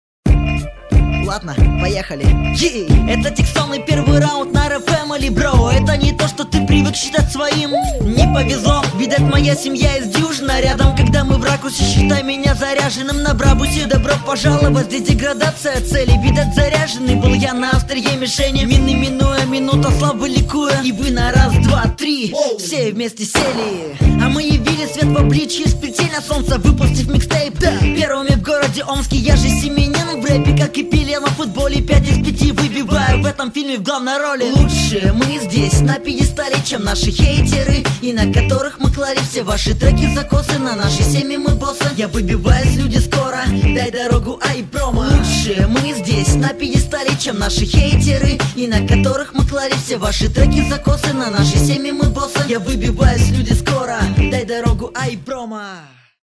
• Баттлы:, 2006-07 Хип-хоп
mp3,882k] Рэп